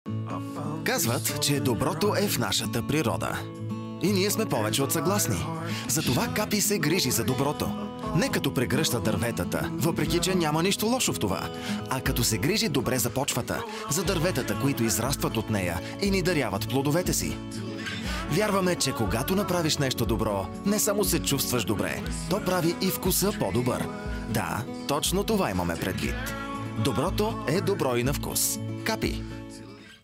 Commercial, Natural, Cool, Warm, Corporate
Commercial